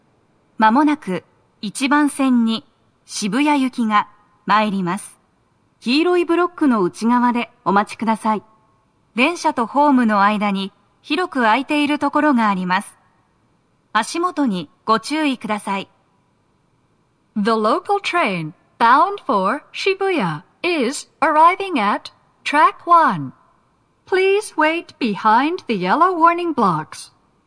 スピーカー種類 TOA天井型
🎤おススメ収録場所 1番線…4号車-5号車付近スピーカー/2番線…最前部付近スピーカー
鳴動は、やや遅めです。
接近放送1